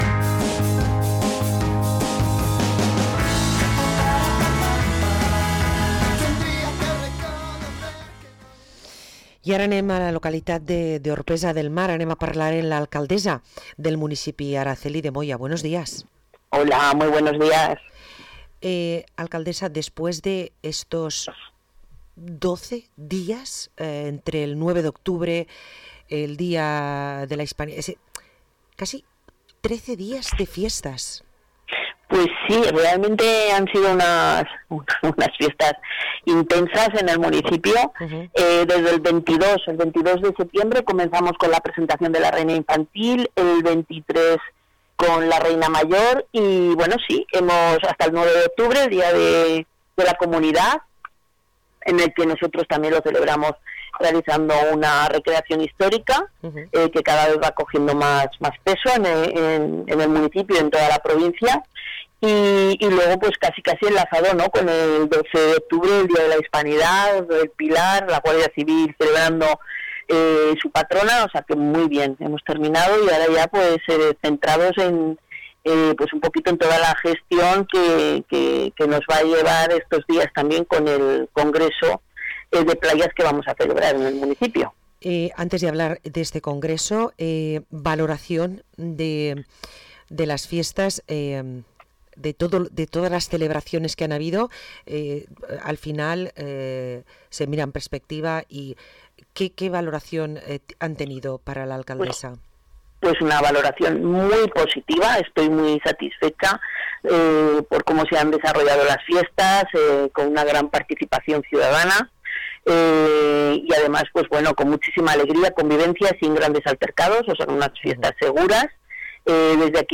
Parlem amb l’alcaldessa d´Orpesa, Araceli de Moya i la regidora de platges Isabel Moya